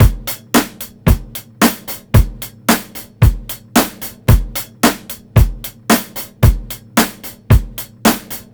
IAR BEAT 1-R.wav